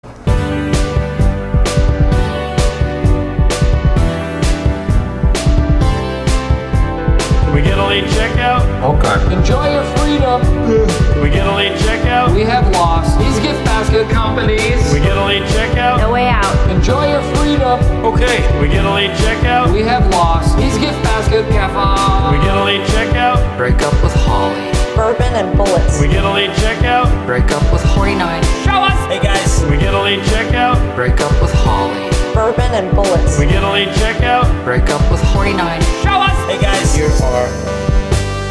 Instrumentalny